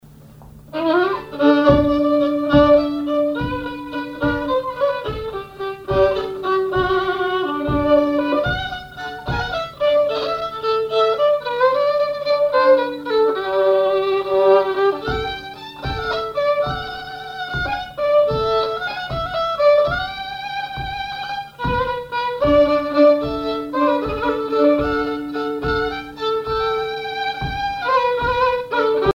violoneux, violon
valse musette
instrumentaux au violon mélange de traditionnel et de variété
Pièce musicale inédite